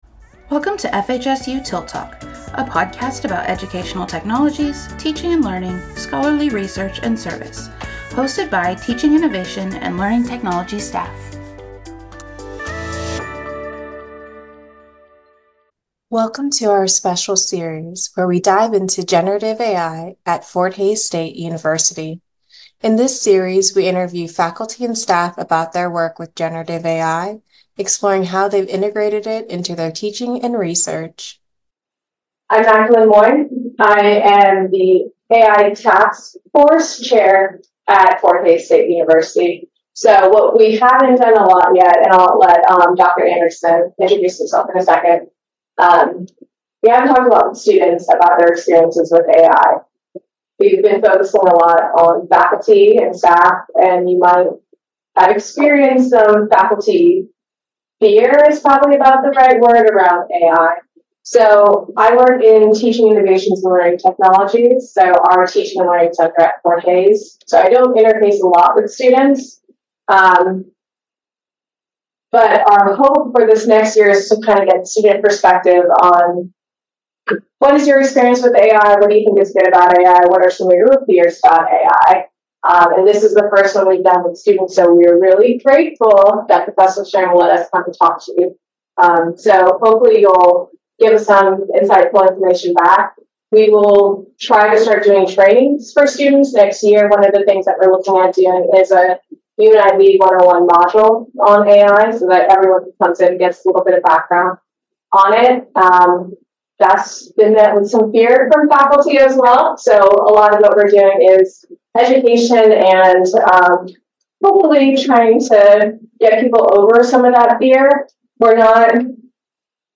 Join FHSU students as they share their experiences and views on AI in the classroom. This candid discussion explores the shifting attitudes of faculty, ethical concerns, and the potential impact of AI on teaching and learning, offering valuable insights for students and educators navigating this new frontier.